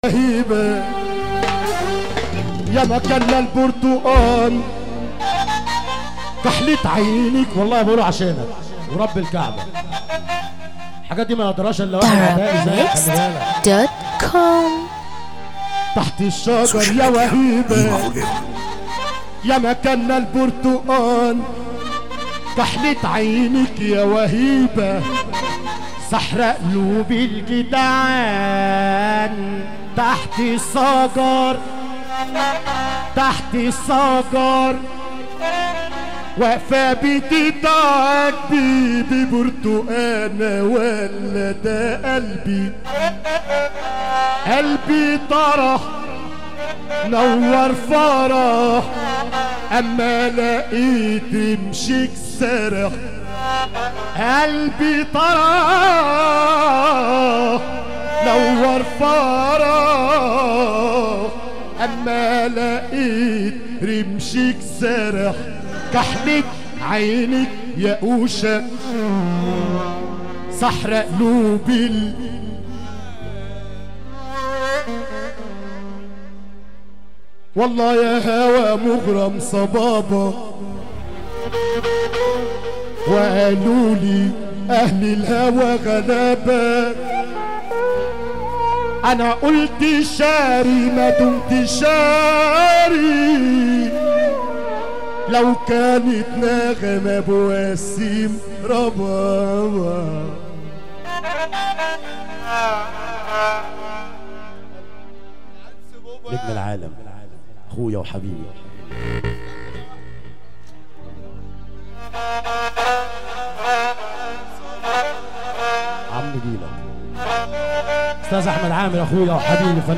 دويتو